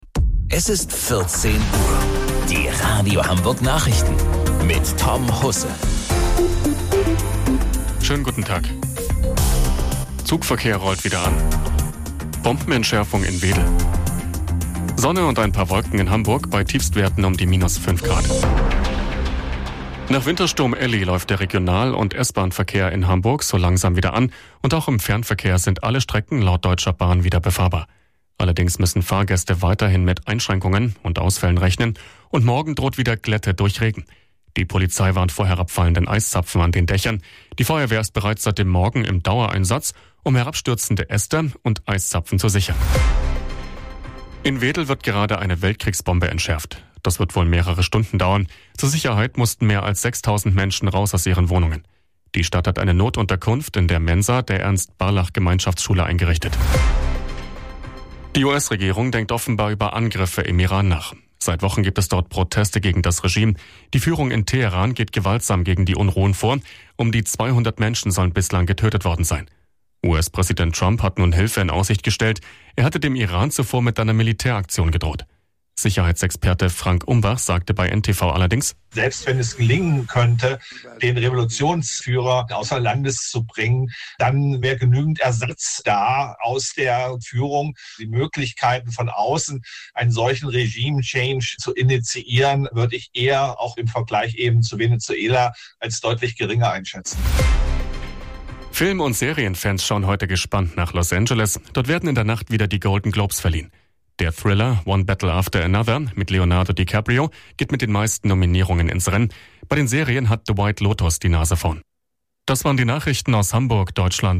Radio Hamburg Nachrichten vom 11.01.2026 um 14 Uhr